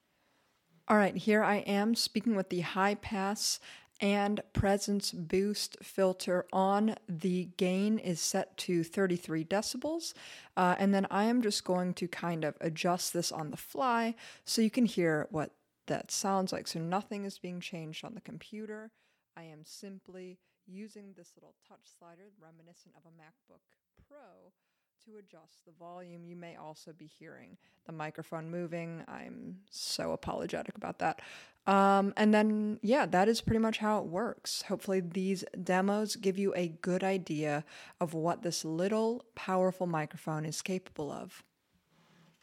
Shure-MV7_high-pass-presence-boost-microphone-demo.mp3